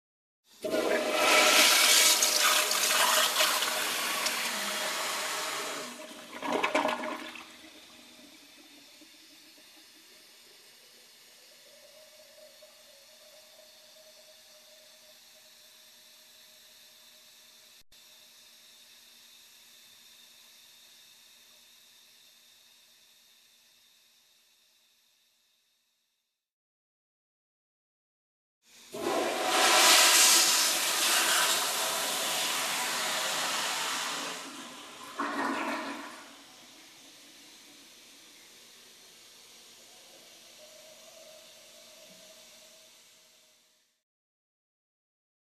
На этой странице собраны звуки, характерные для общественных туалетов: журчание воды, работа сантехники, эхо шагов по кафелю и другие бытовые шумы.
Звуки общественного туалета: смыв унитаза и наполнение бачка